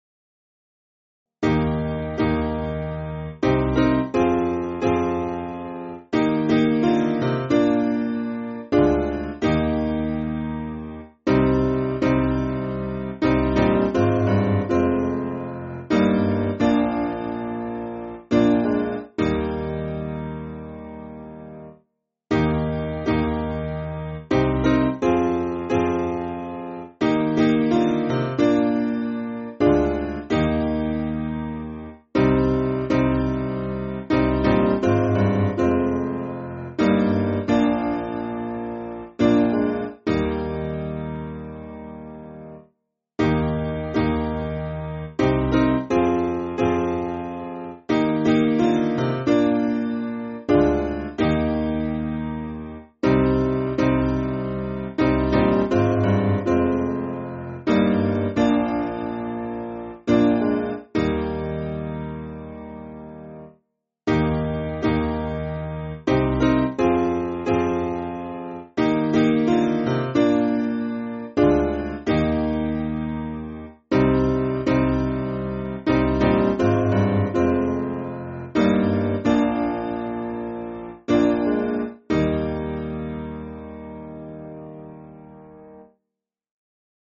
Simple Piano
(CM)   4/Eb